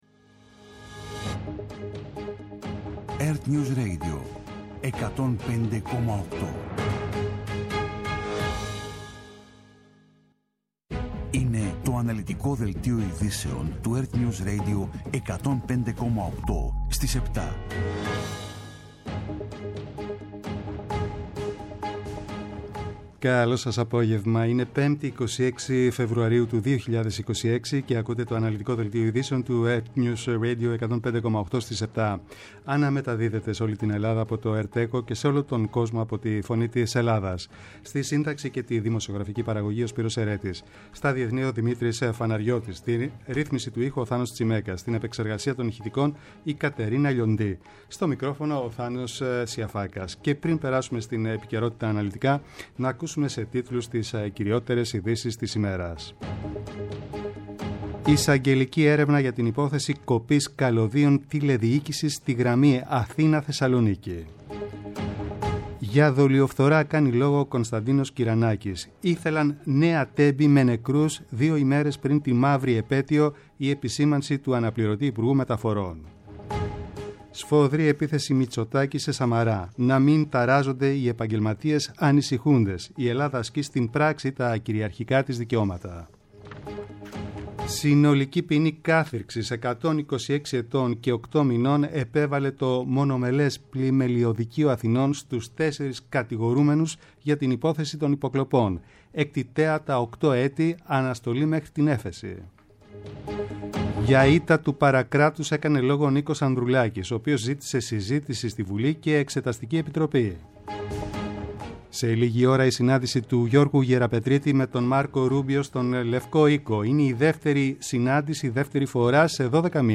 Το αναλυτικό ενημερωτικό μαγκαζίνο στις 19:00. Με το μεγαλύτερο δίκτυο ανταποκριτών σε όλη τη χώρα, αναλυτικά ρεπορτάζ και συνεντεύξεις επικαιρότητας.